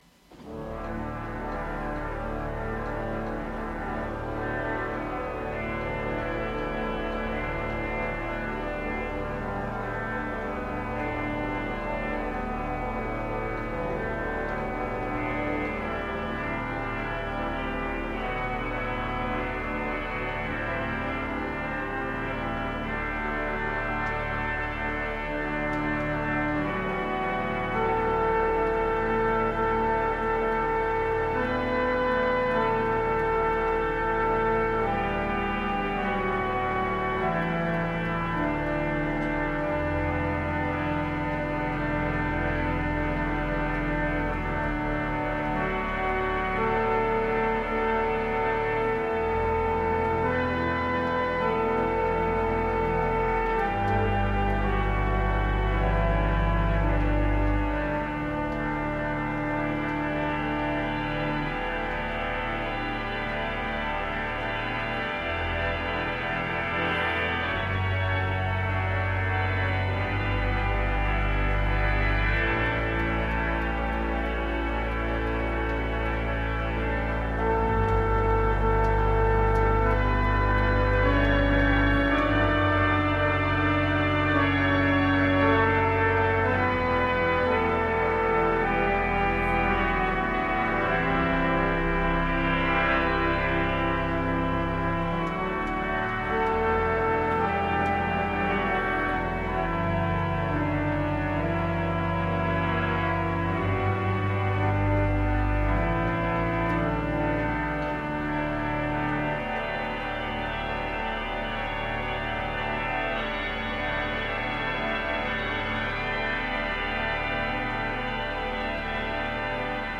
After an interval of many years, I was able recently to renew my acquaintance with the Lewis organ at St. George Cullercoats. It was in need of a tune, and there were some issues with the Swell 2' ciphering, but it was still magnificent as ever. I had a go at recording a few bits (one take jobs, no editing), and there is much that can be improved about both the playing, the improvising and the recording (anything nearing full Great and the mic couldn't cope!) but I hope the tracks give a flavour of this wonderful organ.